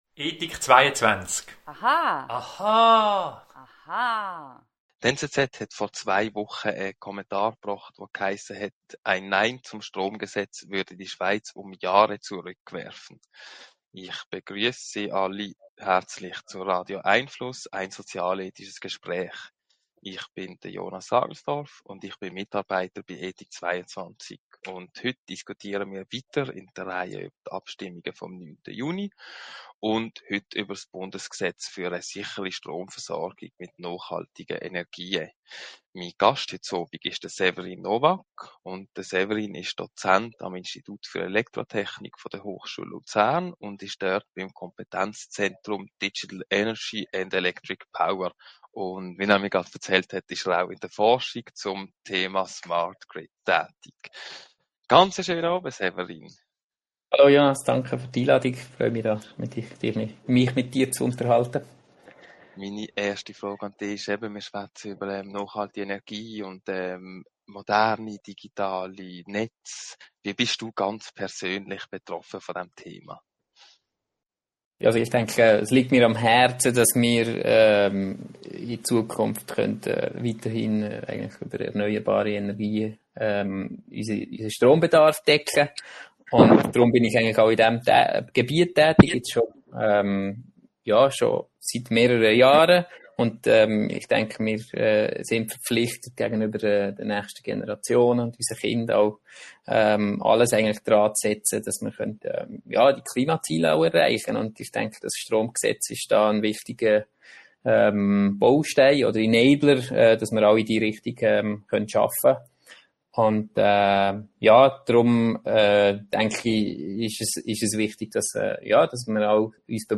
Radio🎙einFluss Audio-Gespräche informiert!